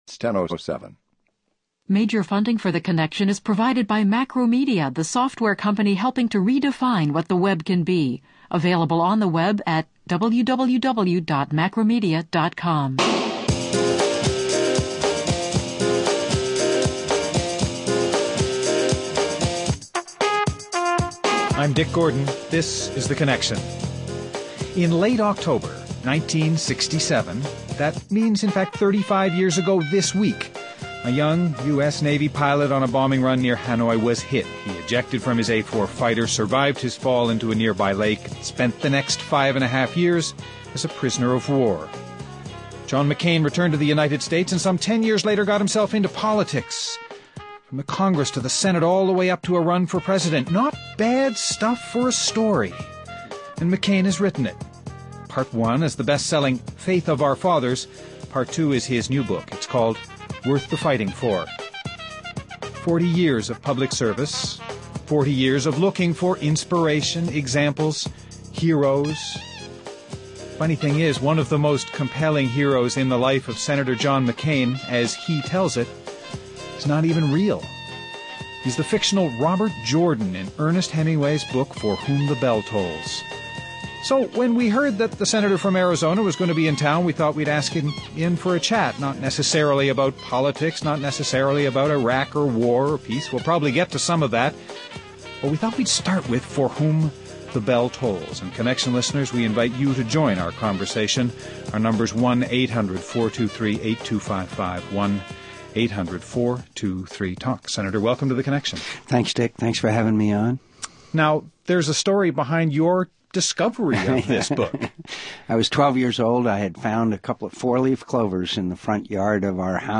Guests: Senator John McCain